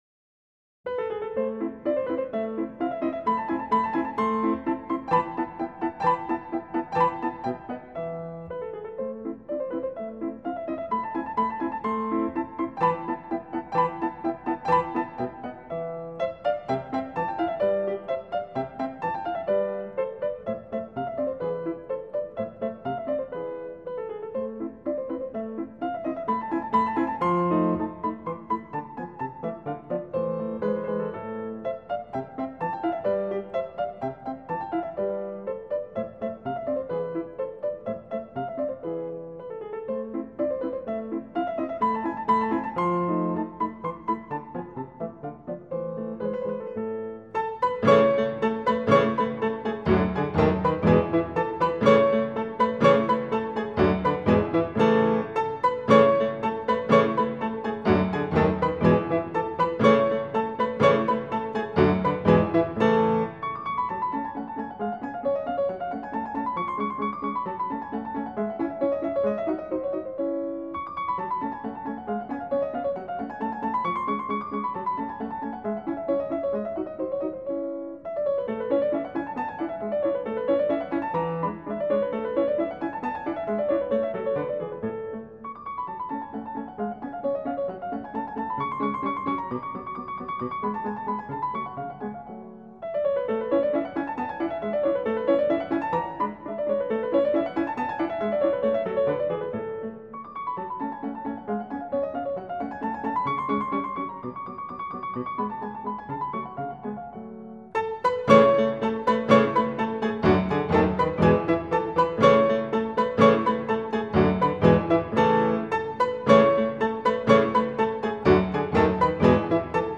Wolfgang Amadeus Mozart - Piano Sonata No. 11 in A major, K.331 - Alla turca.mp3